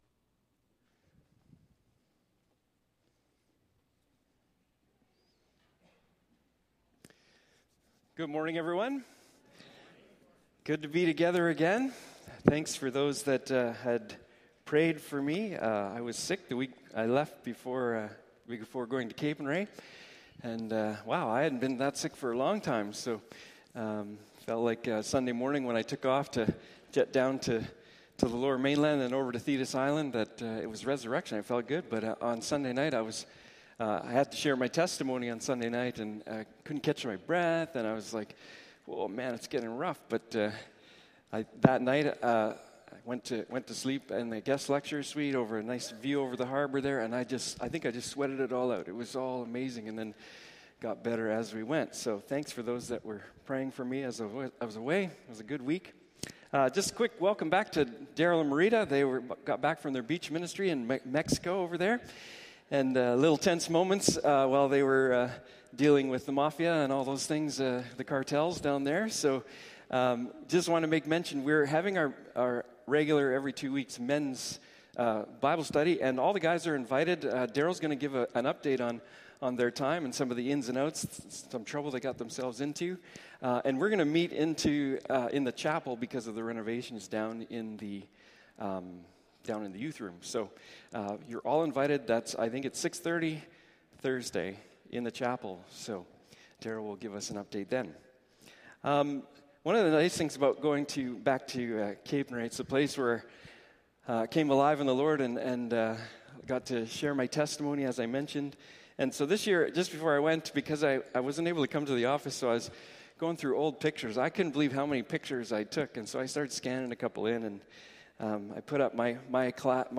The Unfinished Story Passage: Acts 6:5-8, Galatians 5:1, Isaiah 3-16 Service Type: Morning Service